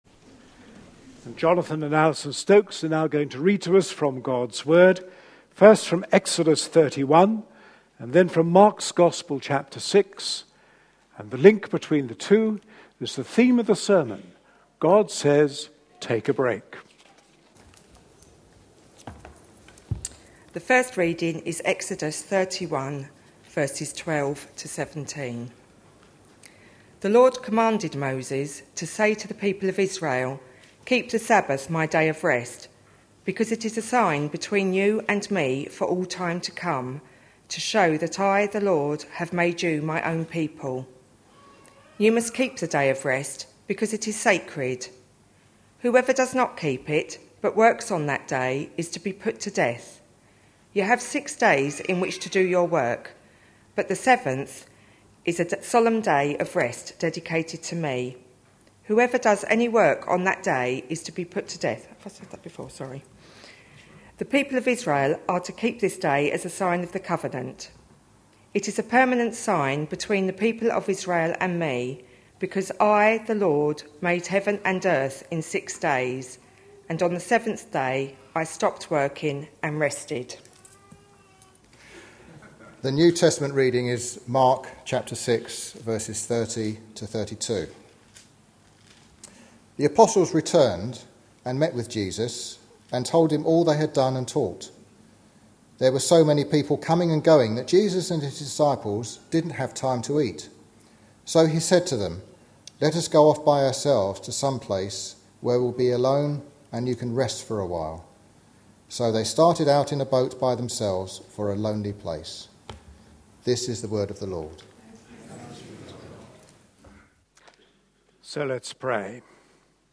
A sermon preached on 28th July, 2013.